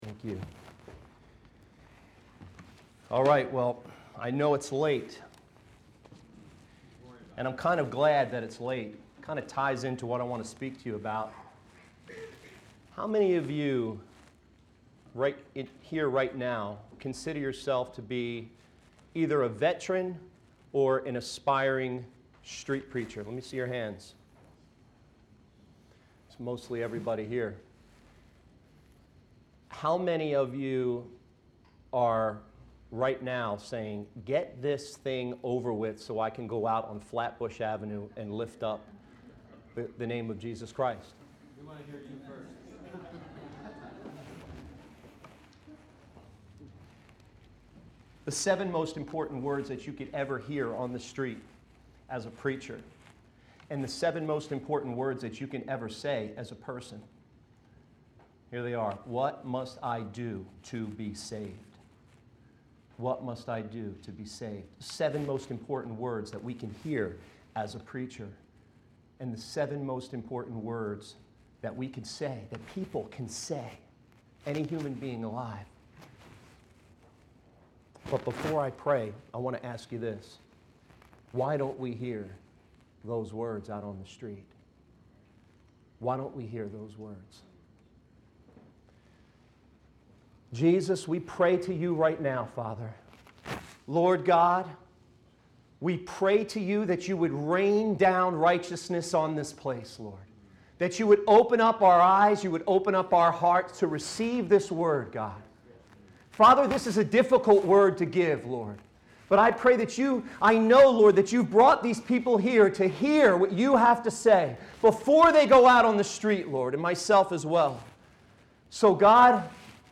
This sermon emphasizes the importance of being called by God before engaging in street preaching, highlighting the need to be set afire by the Holy Spirit, eliminate idolatry, and focus on the love and compassion of Jesus Christ. It challenges street preachers to prioritize their love for Christ above all else, to be consecrated to the cause, and to invite people to come to Jesus for salvation.